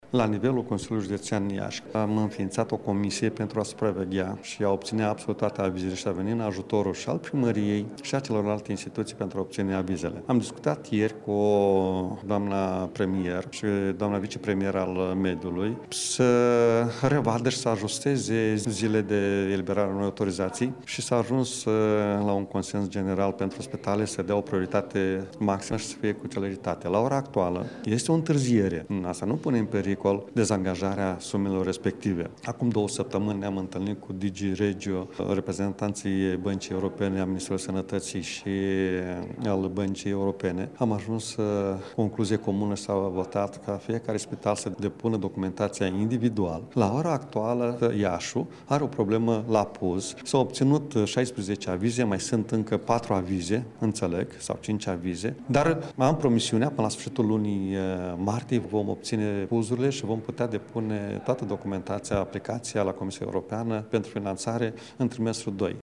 Maricel Popa a mai spus că la Comisia Europeană proiectele vor fi depuse separat, pentru fiecare spital în parte, astfel încât Iaşul să nu fie afectat de problemele existente la Cluj şi Craiova: